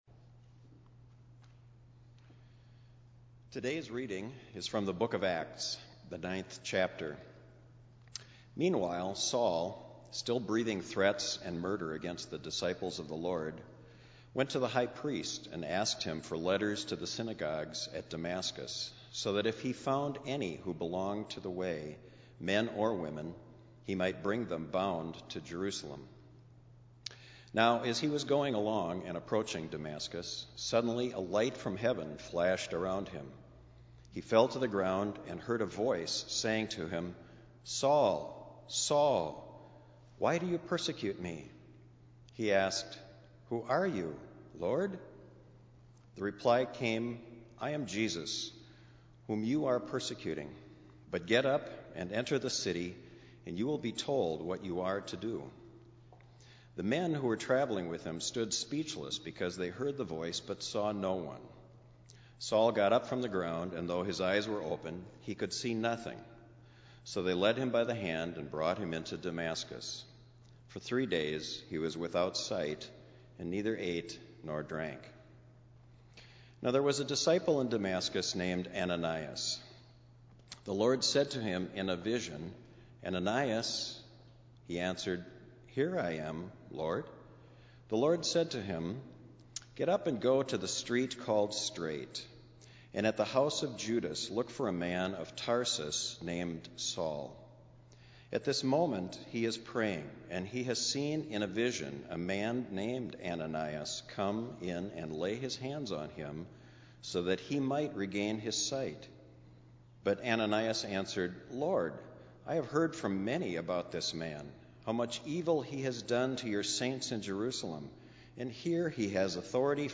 Minneapolis Livestream · Sunday, May 9, 2021 10:15 am